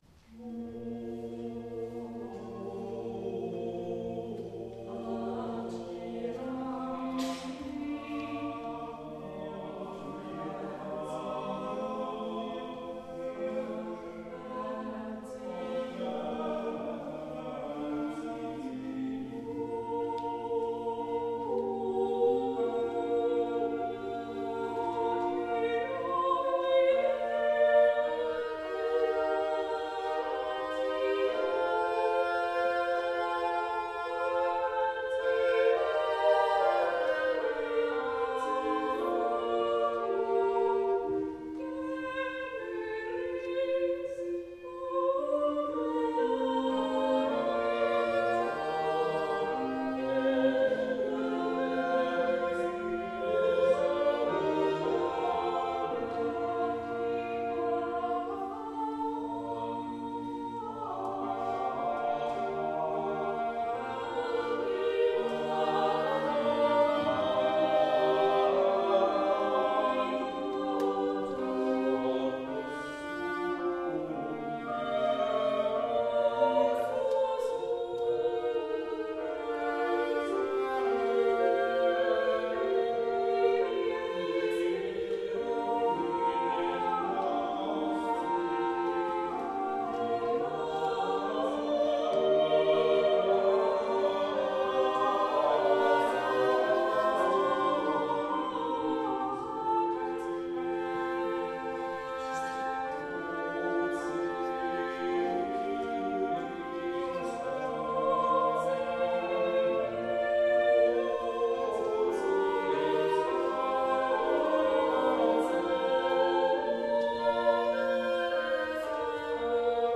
Die Hörbeispiele sind Live-Mitschnitte aus unterschiedlichen
Gesang, Bassblockflöte, Niccolopommer